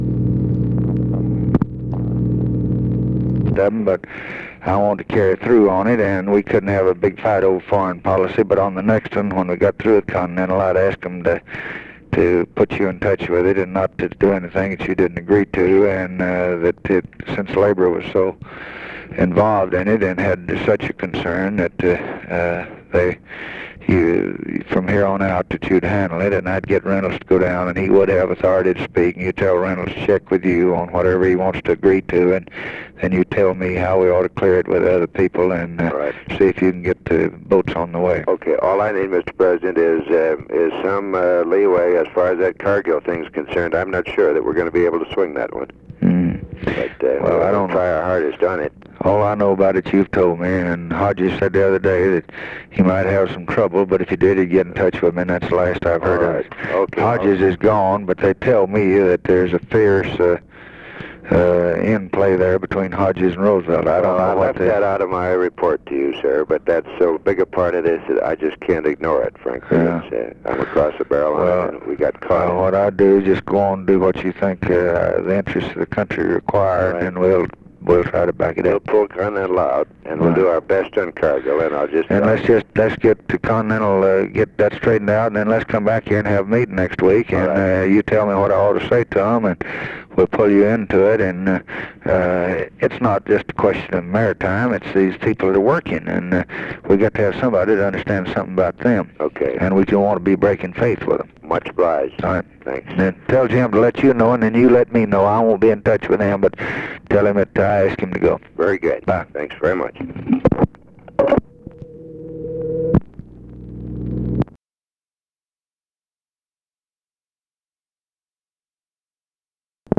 Conversation with WILLARD WIRTZ, February 16, 1964
Secret White House Tapes